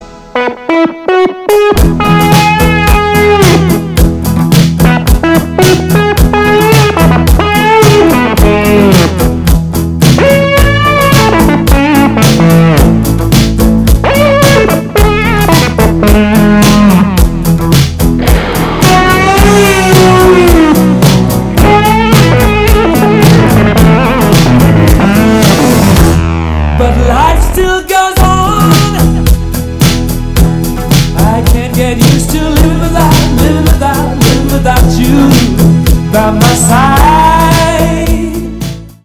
ретро мужской голос 80-е